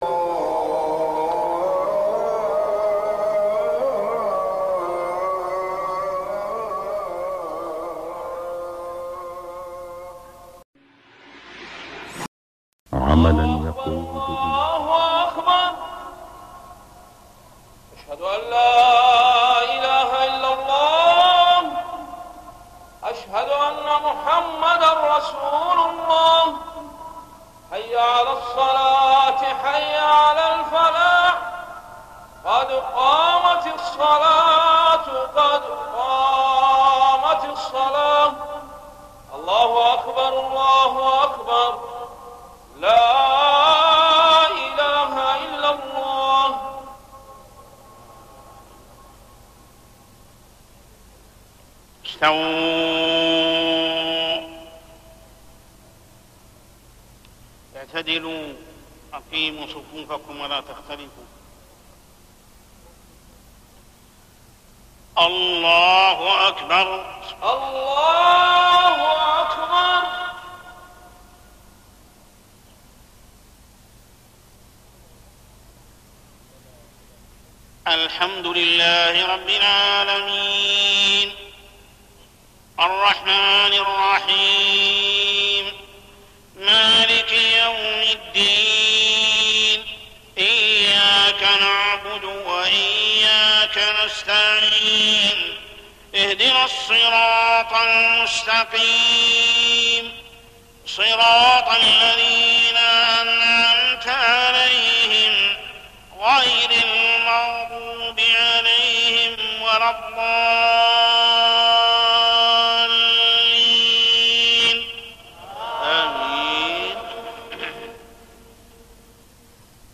صلاة العشاء الأربعاء 15رجب 1419هـ للشيخ محمد السبيل > 1419 🕋 > الفروض - تلاوات الحرمين